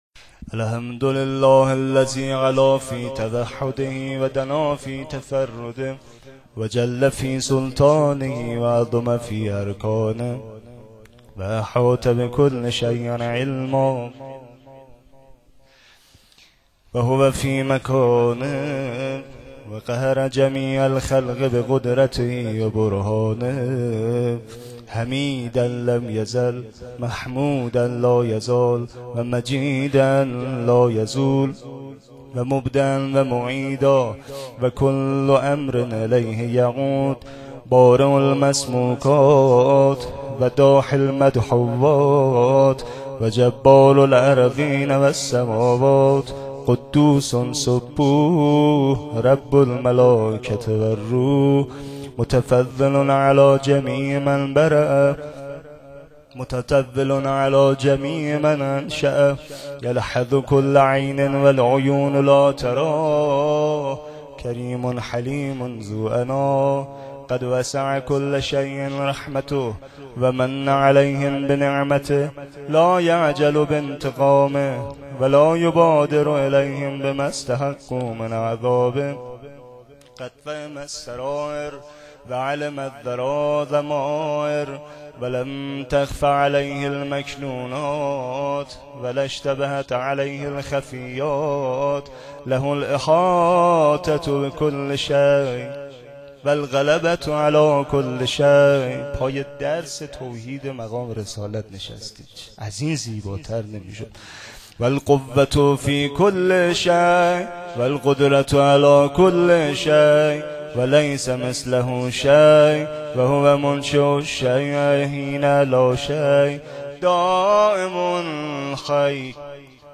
خطبه.wma